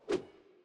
sword 1.wav